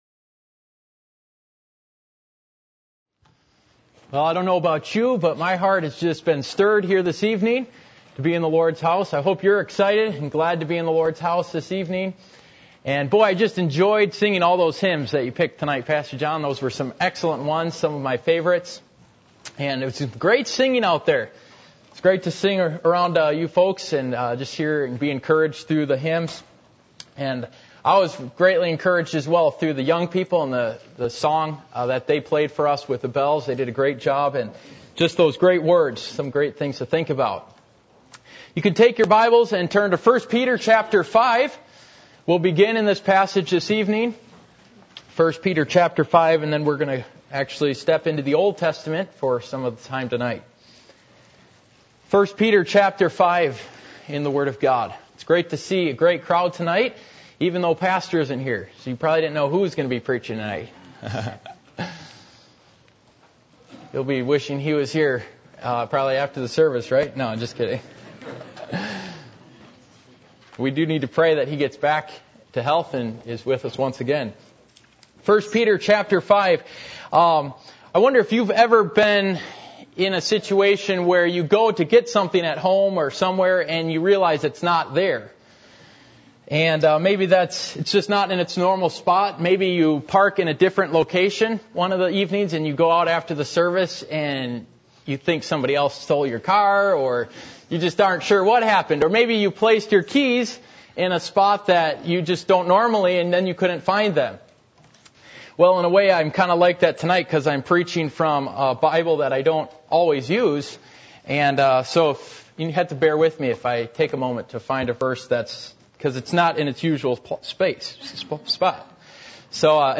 Passage: 1 Peter 5:8 Service Type: Sunday Evening %todo_render% « What Occupies Your Mind?